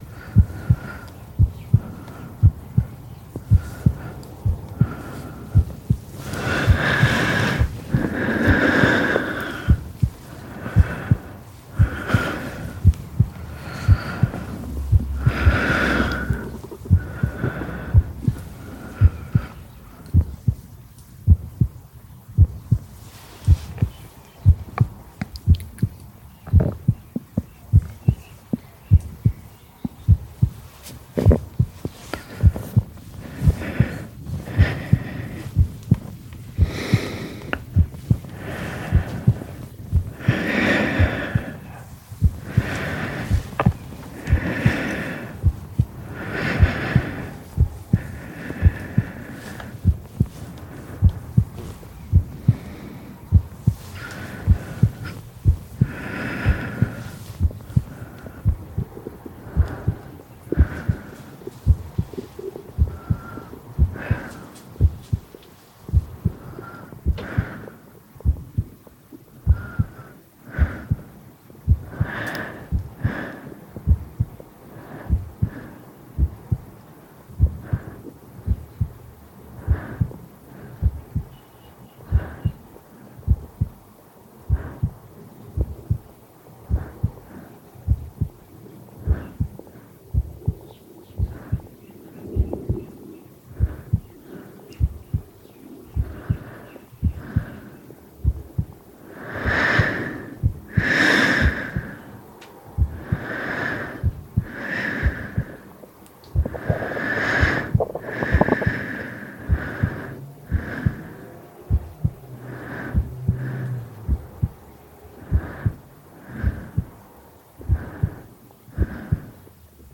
breaths-heart-long-loop.mp3